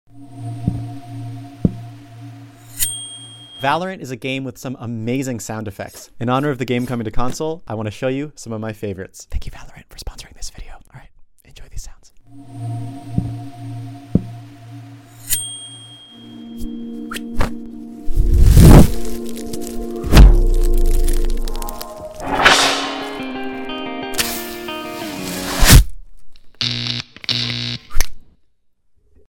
VALORANT with live sound effects! sound effects free download